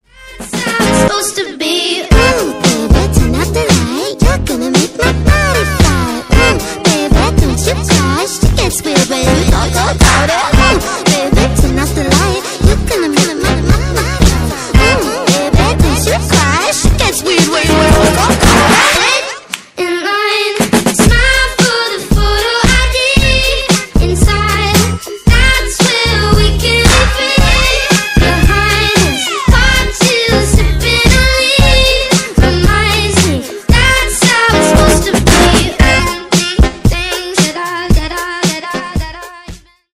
Поп Музыка # Танцевальные
весёлые